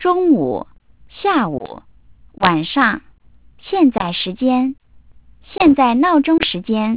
When a frame erasure is detected, all the bits in that frame are  replaced by the lowest quantization level.
- sampling rate : 8 kHz
b) Silence Substitution
reconstructed speech